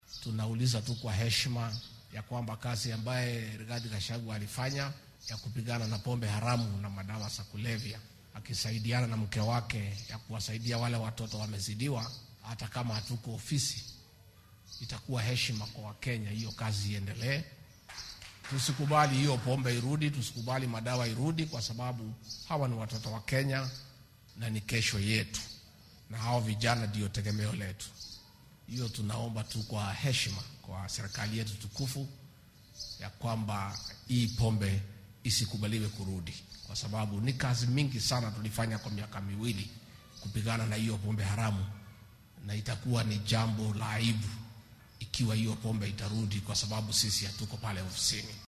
Gachagua ayaa xilli uu munaasabad kaniiseed kaga qayb galay xaafadda Kahawa West ee ismaamulka Nairobi sheegay in adeegsiga daroogada uu burburinaya nolosha dhallinyarada oo ah hoggaamiyaasha mustaqbalka.
Rigathi-Gachagua.mp3